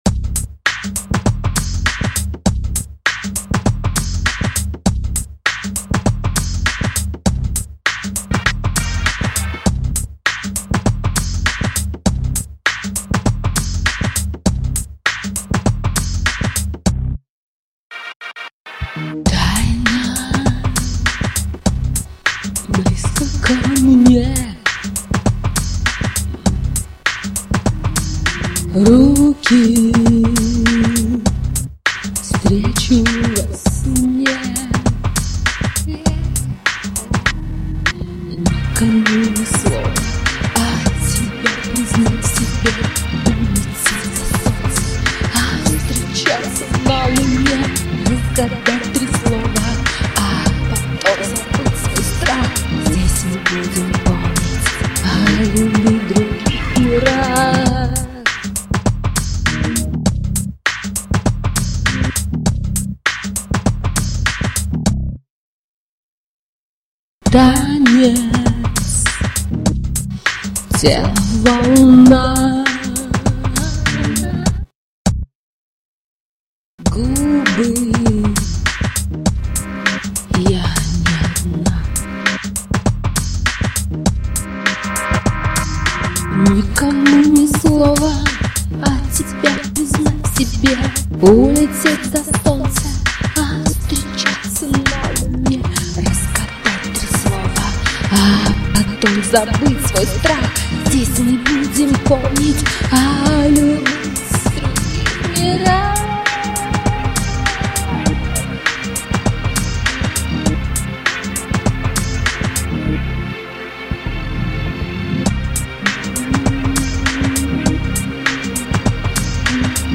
попса.